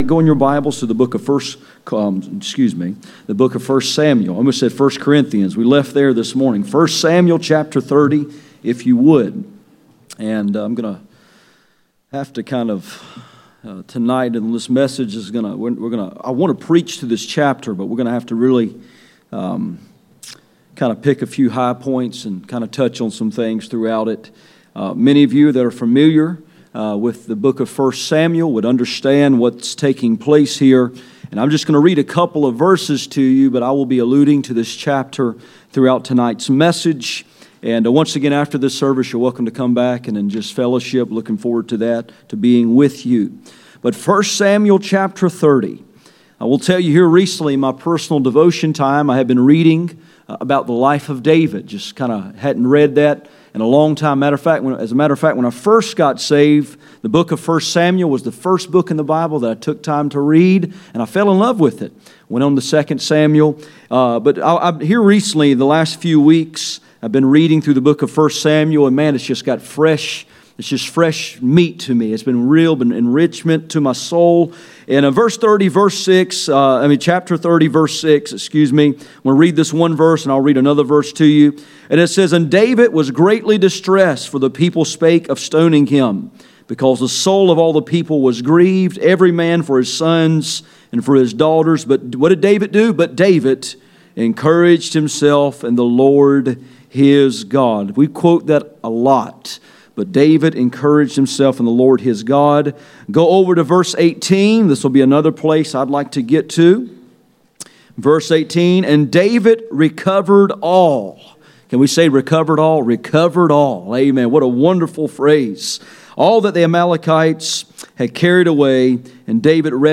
Passage: 1 Samuel 30:1-31 Service Type: Sunday Evening